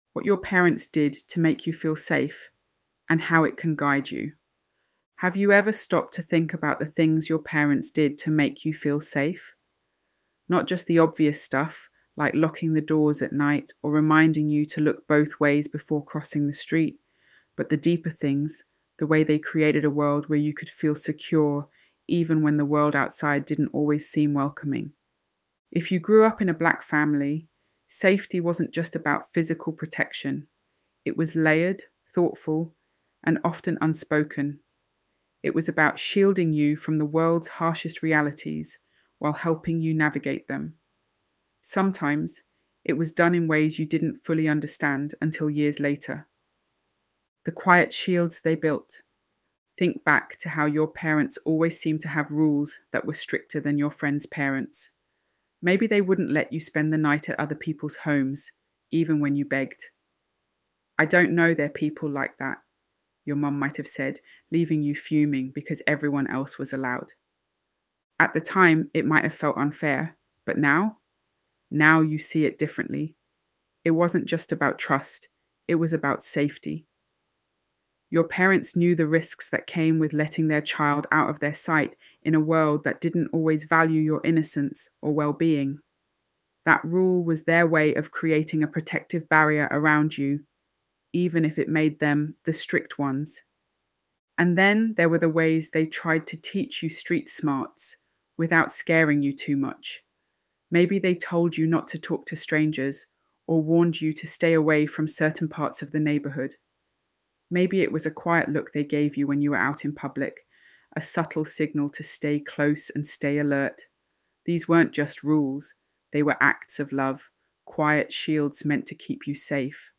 ElevenLabs_What_Your_Parents_Did_to_Make_You_Feel_Safe.mp3